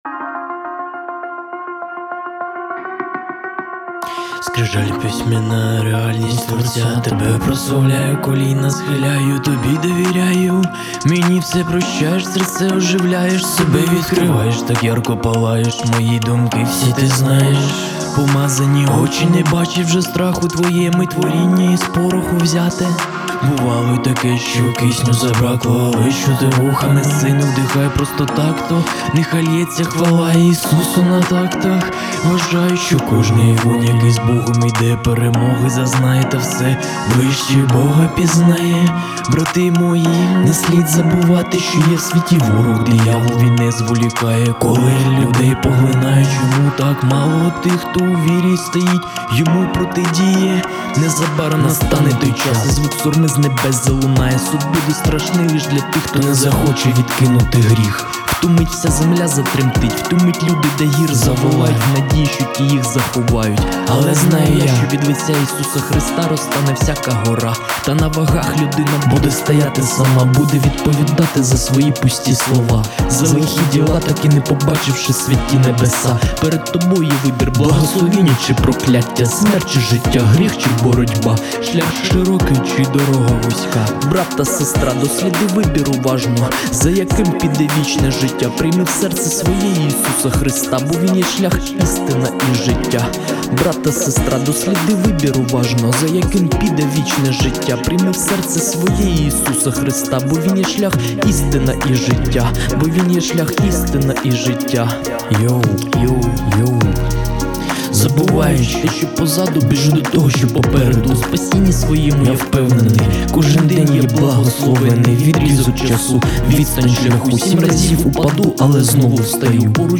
74 просмотра 63 прослушивания 2 скачивания BPM: 102